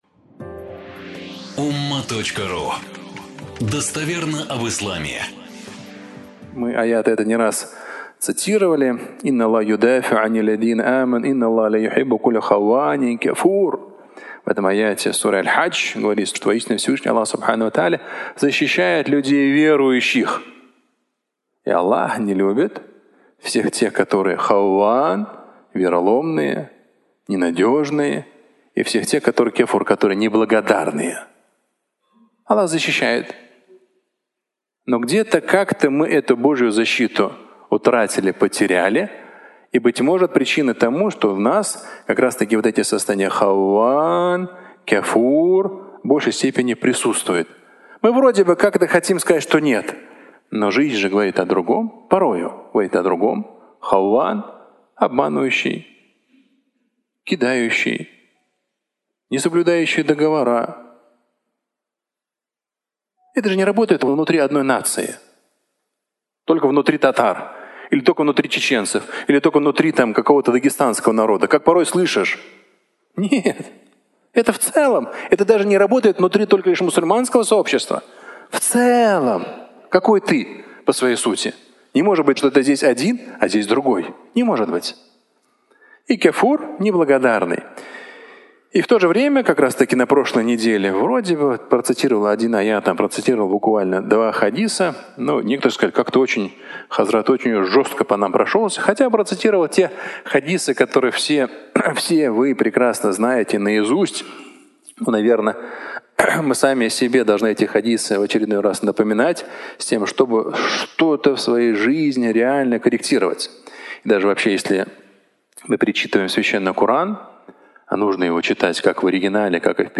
Пытаются обмануть Аллаха (аудиолекция)
Фрагмент пятничной проповеди